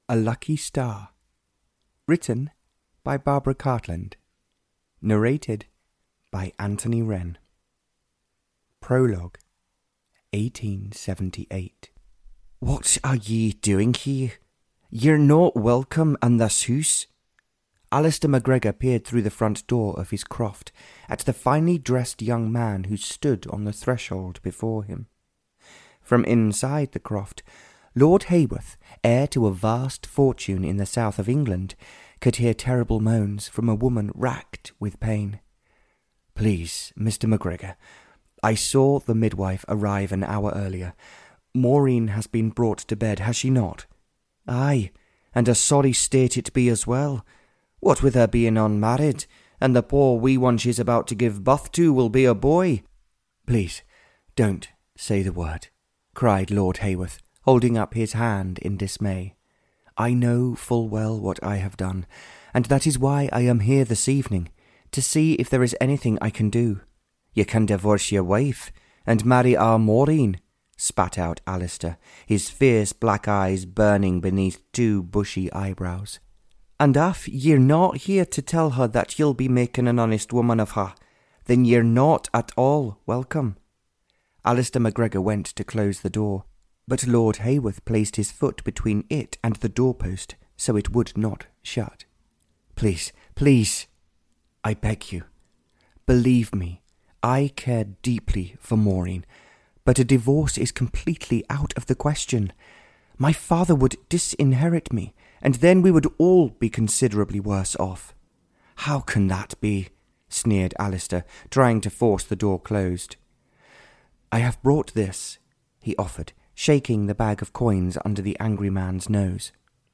A Lucky Star (Barbara Cartland's Pink Collection 78) (EN) audiokniha
Ukázka z knihy